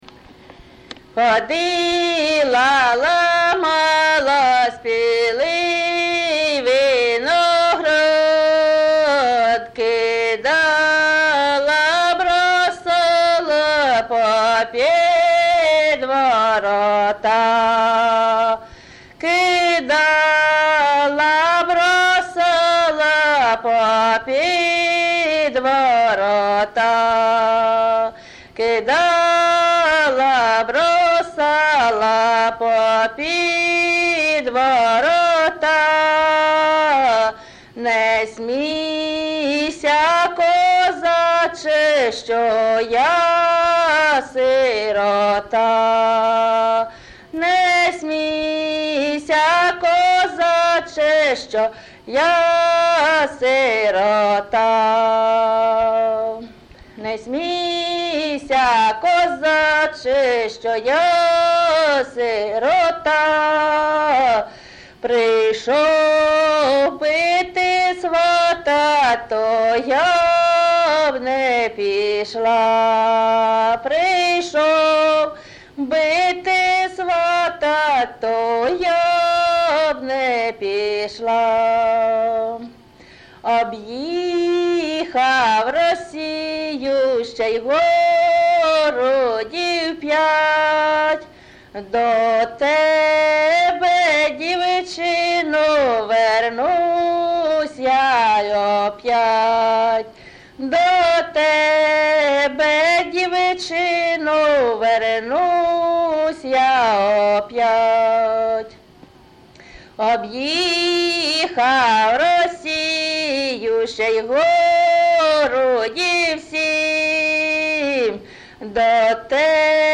ЖанрПісні з особистого та родинного життя
Місце записус. Яблунівка, Костянтинівський (Краматорський) район, Донецька обл., Україна, Слобожанщина